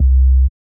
br808.wav